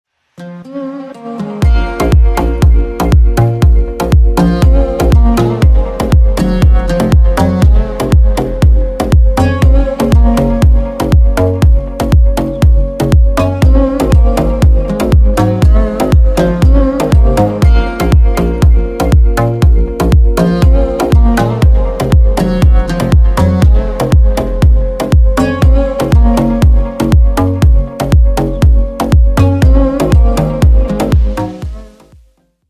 • Качество: 320, Stereo
восточные мотивы
dance
спокойные
без слов
Красивый дип-хаус с восточными нотками